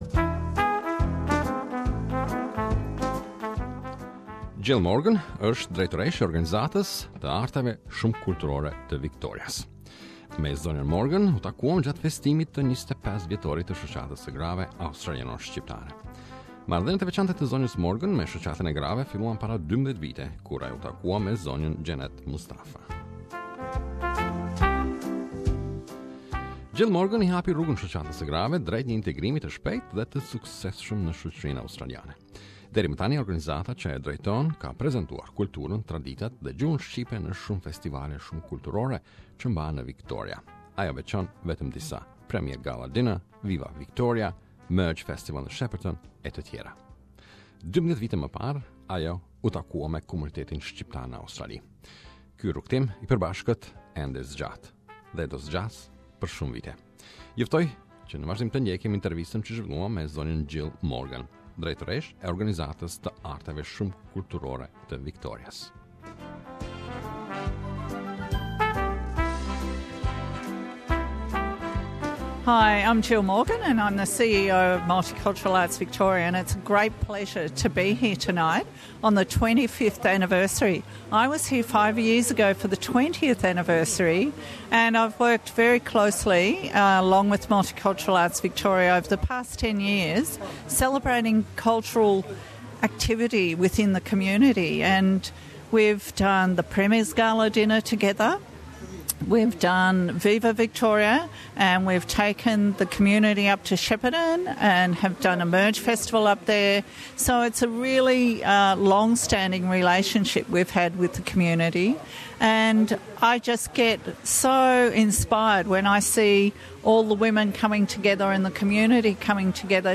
We had a chance to inteview